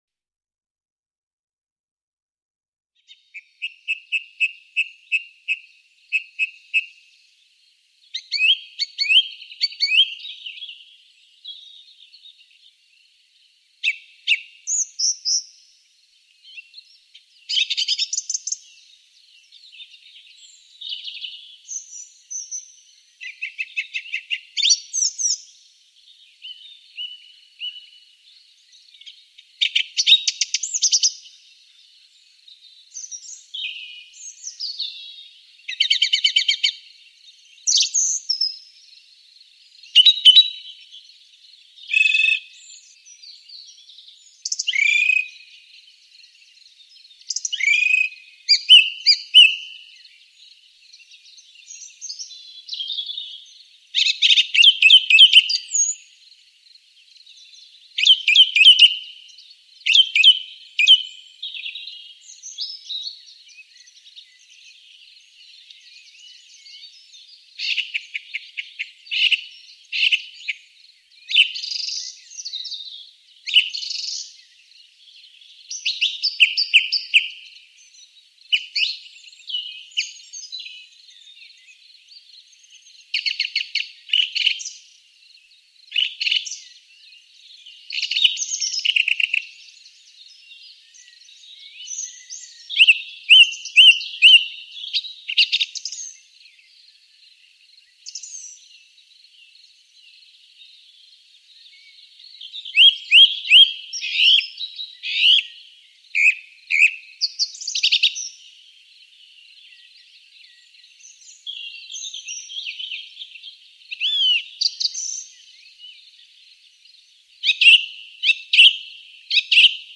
Singdrossel
Sie vertilgen sehr viel Raupen und andere Sch�dlinge, halten sich aber auch an Beeren und Fr�chte. Ihr Zuhause ist nur der Wald und mit ihrem wohlklingenden Gesang beleben sie unsere Natur.